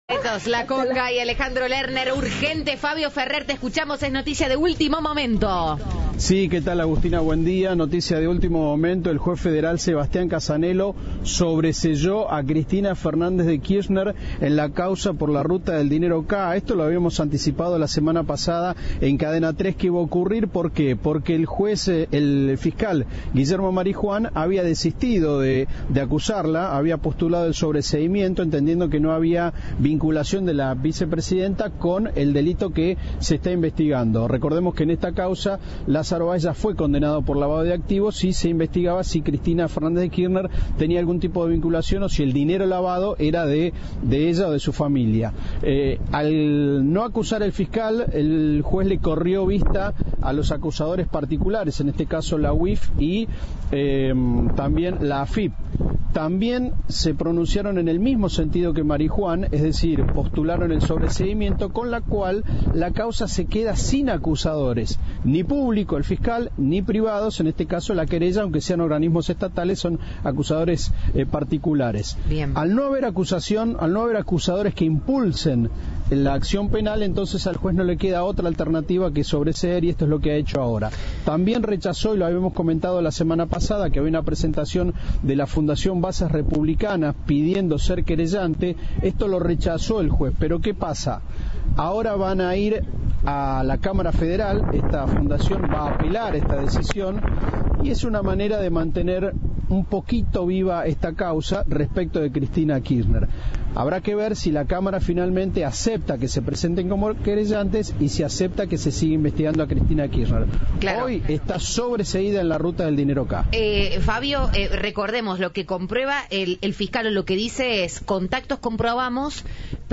Informe
Entrevista